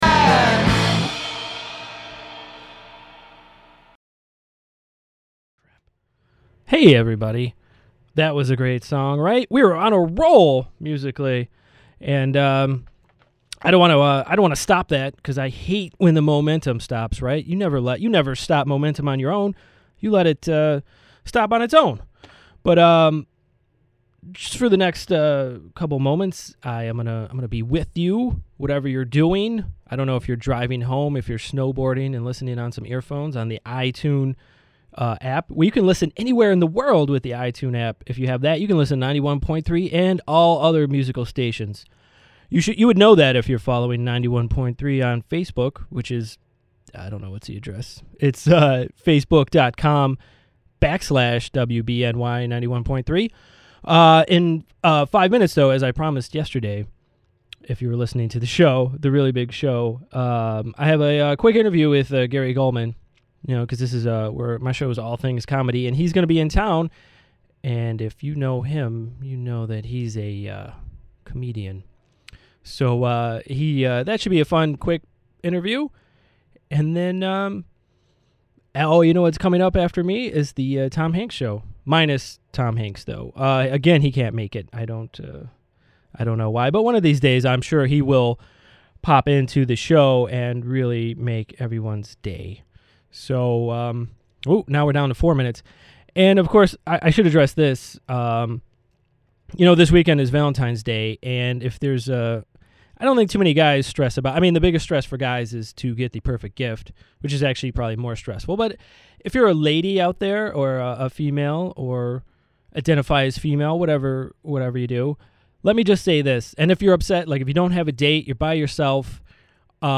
comedy interview